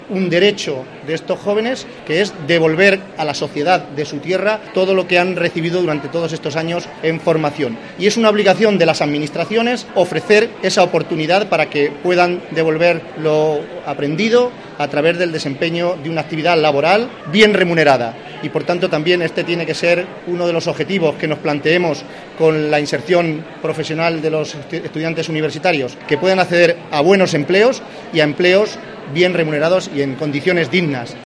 José Manuel Caballero, presidente Diputación CR